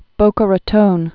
(bōkə rə-tōn)